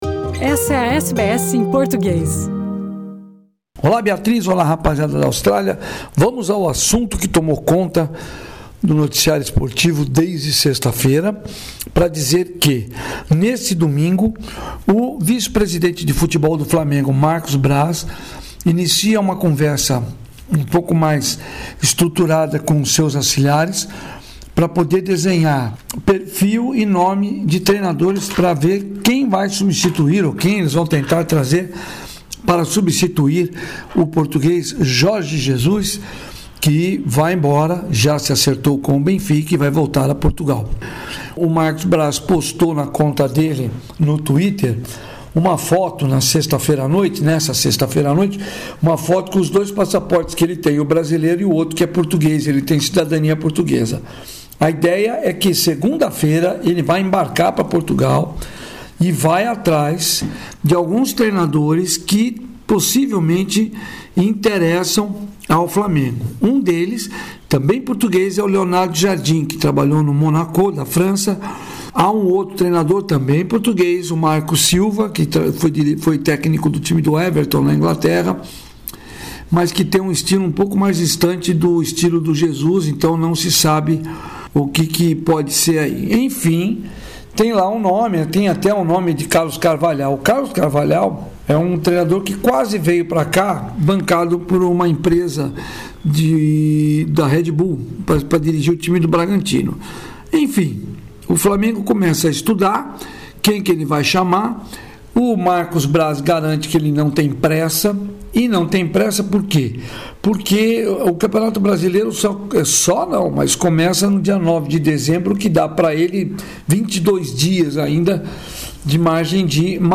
Neste boletim